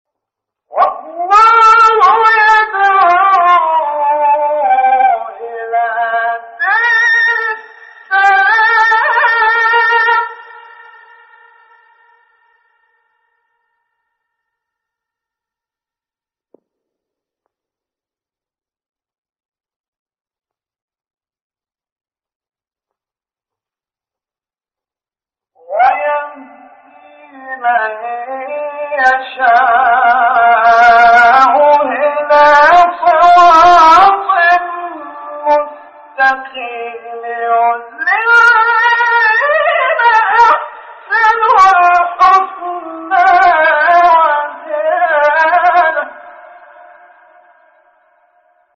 گروه شبکه اجتماعی: فرازهایی از تلاوت قاریان ممتاز مصری در زیر ارائه می‌شود.